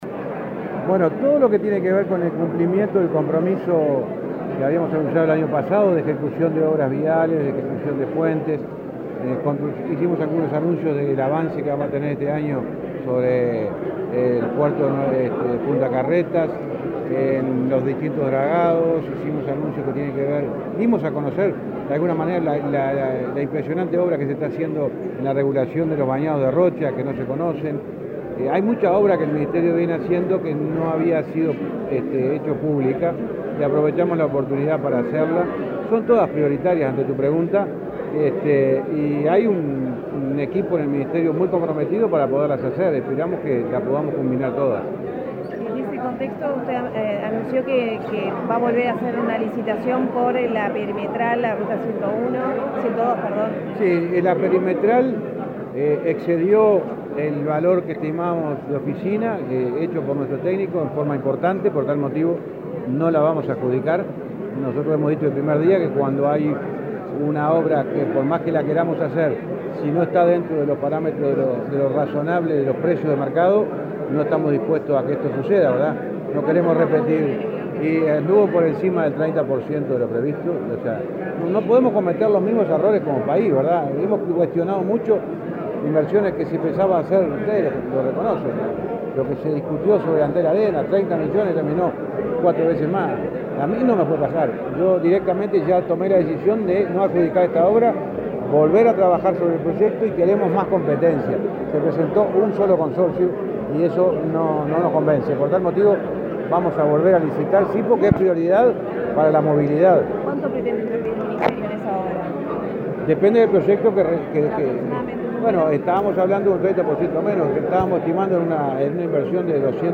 Declaraciones del ministro de Transporte, José Luis Falero
Luego dialogó con la prensa.